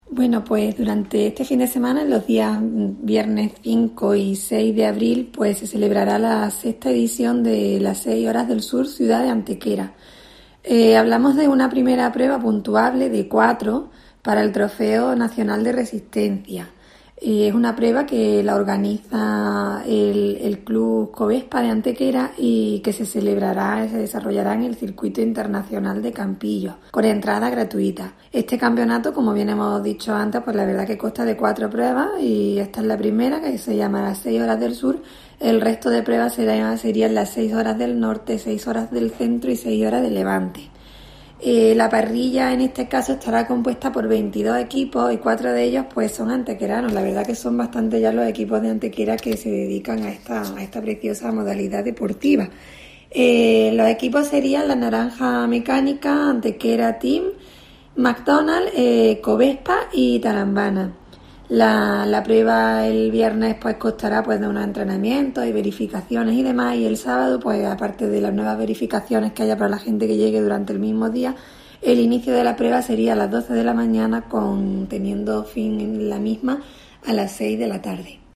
Cortes de voz E. Galán 535.66 kb Formato: mp3